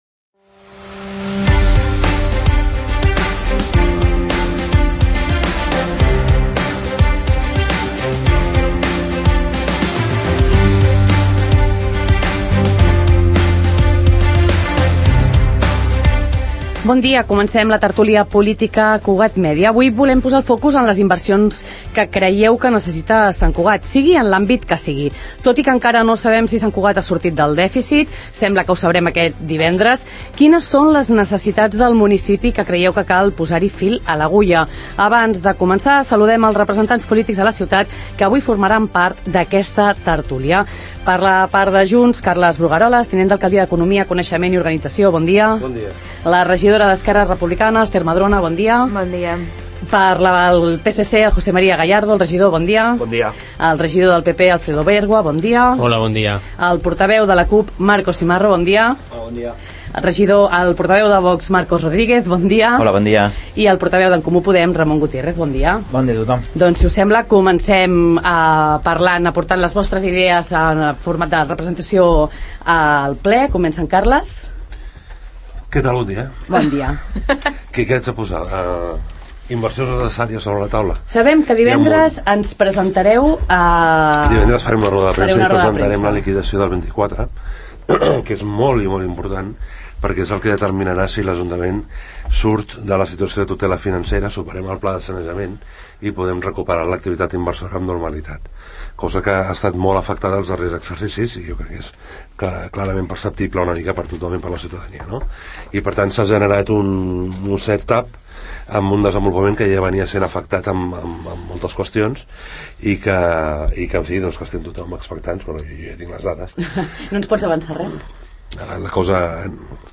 El tinent d’Alcaldia d’Economia, Carles Brugarolas, ha afirmat aquest dimecres al matí, durant la tertúlia política del magazín Faves Comptades, que Sant Cugat “va en la bona direcció”.